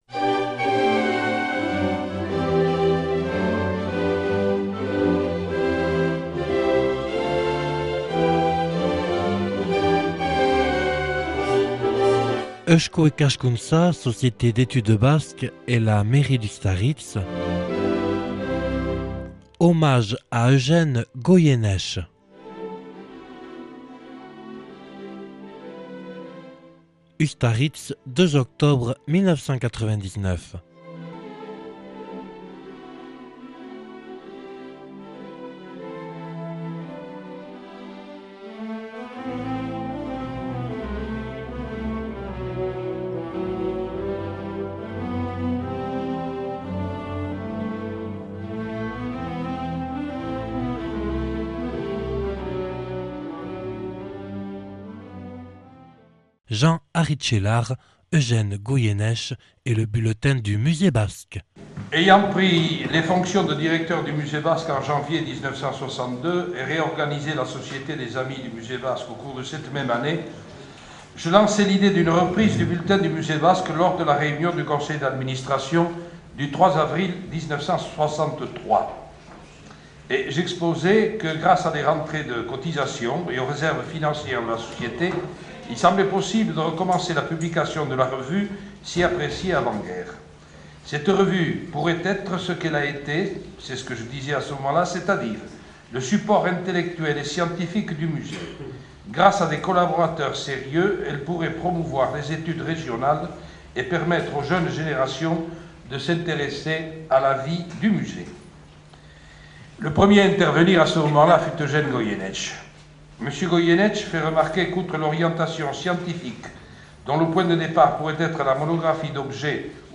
(Enregistré le 02/10/1999 à Ustaritz).